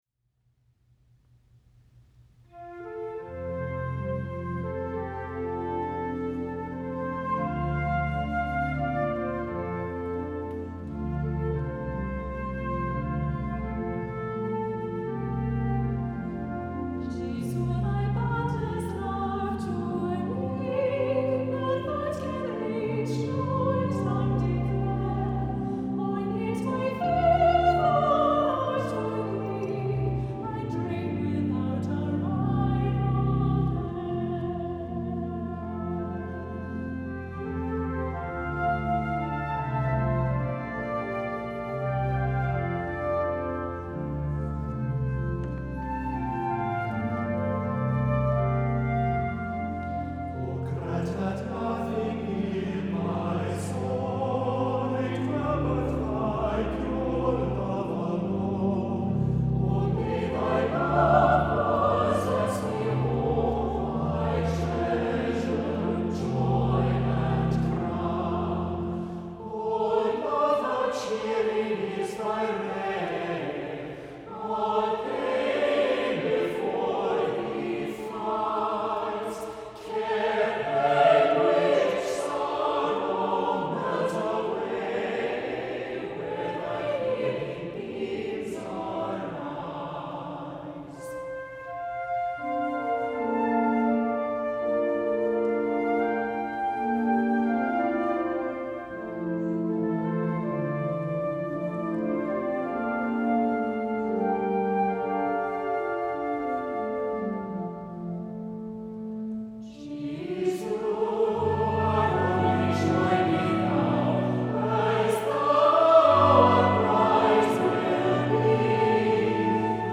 • Music Type: Choral
• Voicing: SATB
• Accompaniment: Flute, Organ